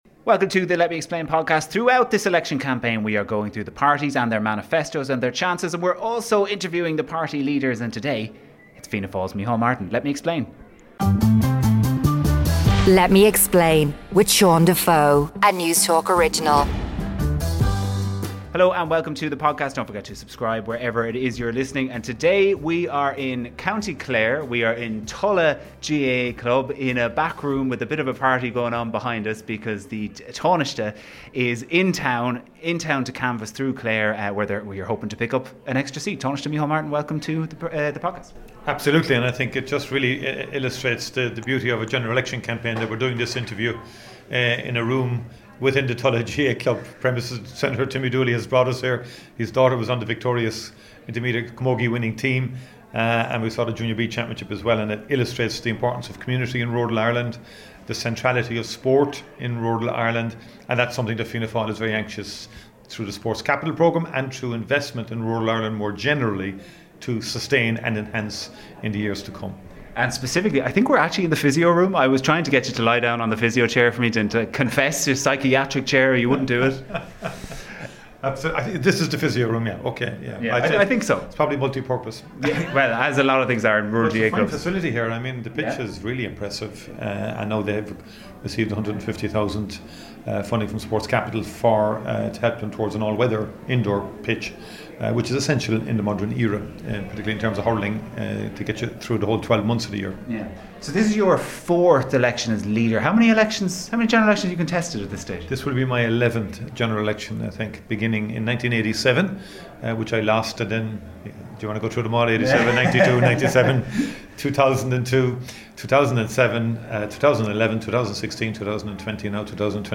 148. The Leaders' Interview: Micheál Martin